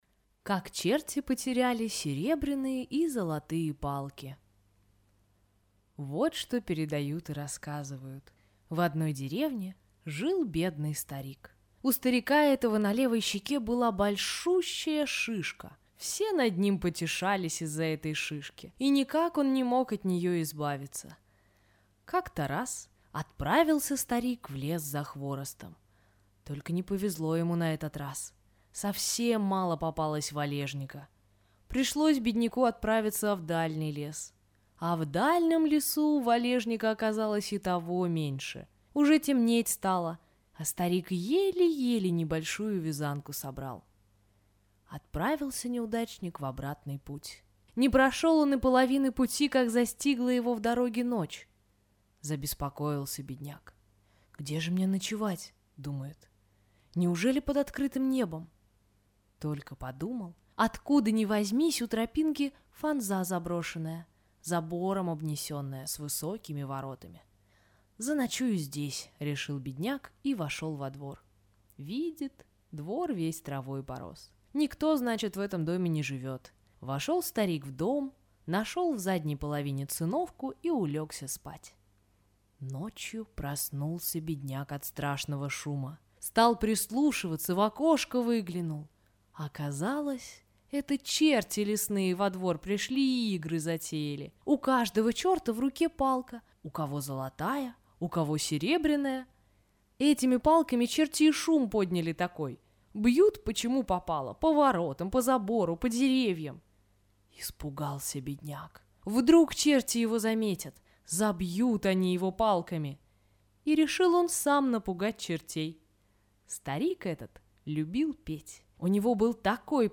Как черти потеряли золотые и серебряные палки – корейская аудиосказка